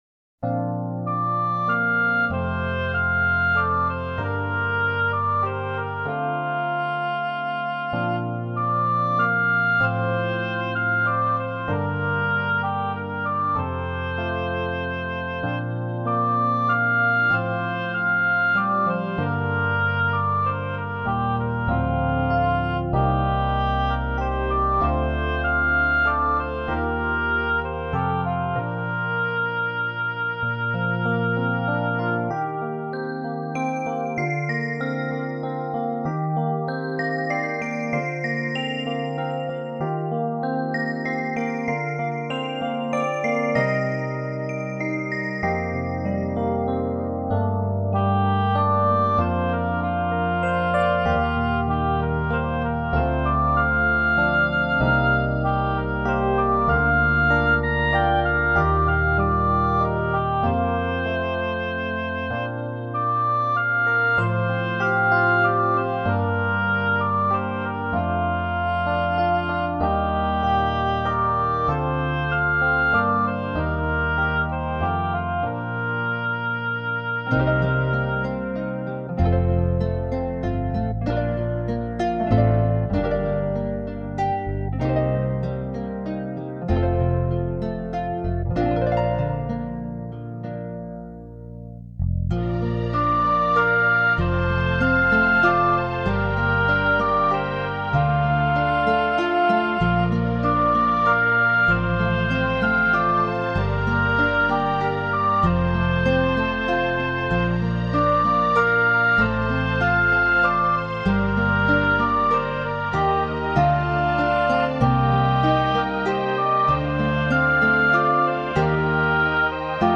以清麗音符所譜寫的生命之歌，旋律自然動人，
清澈如水的鋼琴、紓緩深情的大提琴交織出
一片舒適無壓力的天空，任人自在翱翔。